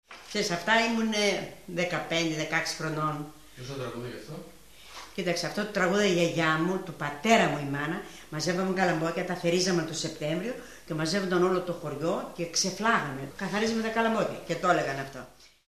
(παραδοσιακό της ξενιτειάς)